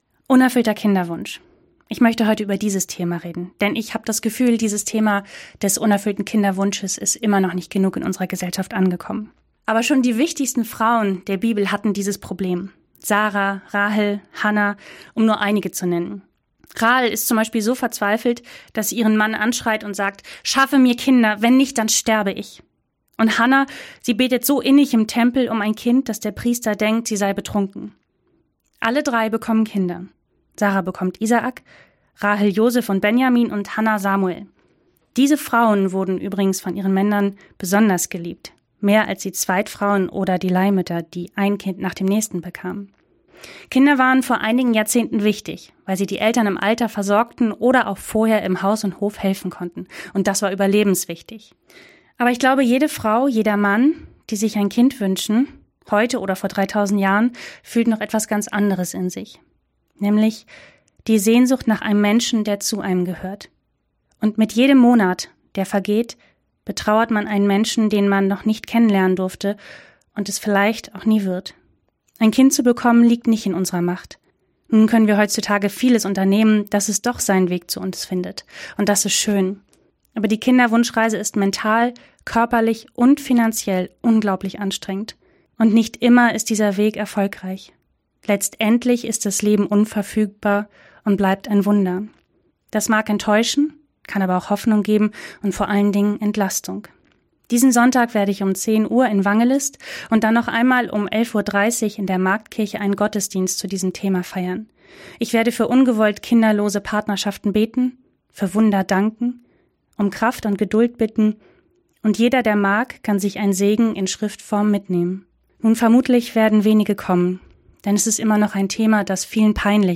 Radioandacht vom 18. Juli